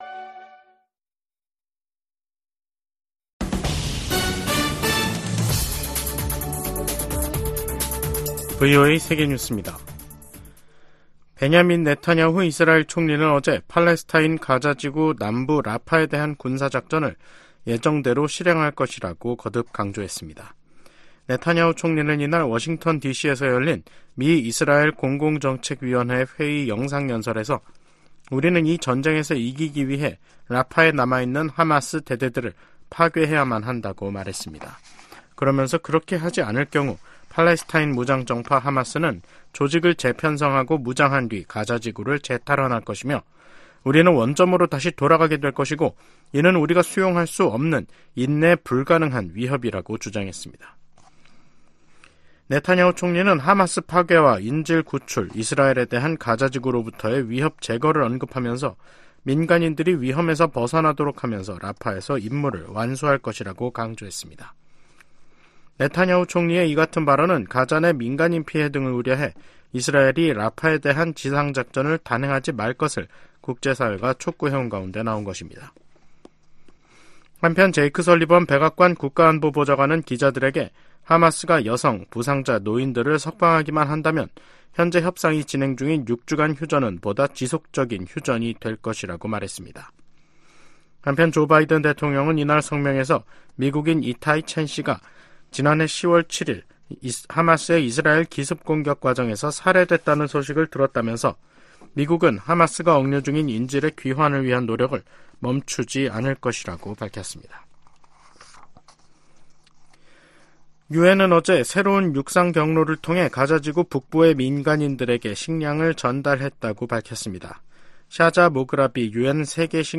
VOA 한국어 간판 뉴스 프로그램 '뉴스 투데이', 2024년 3월 13일 2부 방송입니다. 긴밀해지는 북한-러시아 관계가 김정은 국무위원장을 더 대담하게 만들 수 있다고 애브릴 헤인스 미 국가정보국장이 말했습니다.